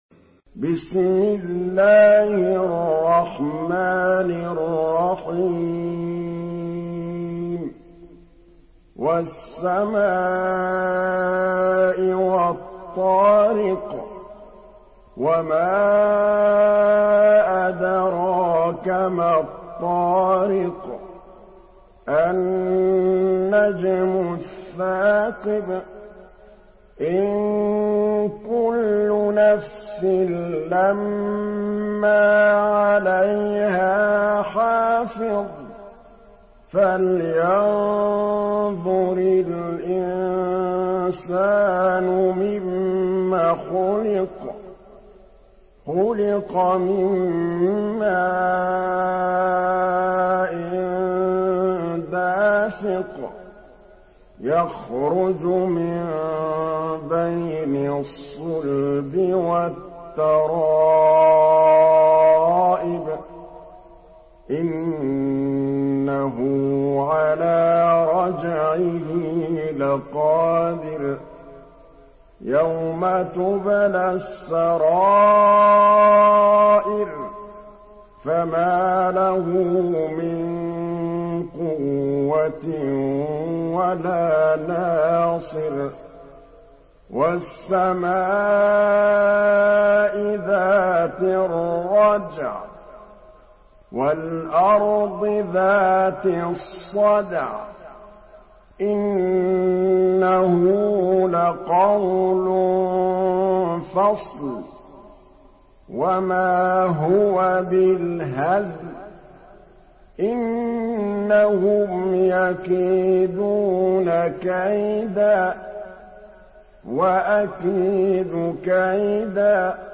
Surah At-Tariq MP3 by Muhammad Mahmood Al Tablawi in Hafs An Asim narration.
Murattal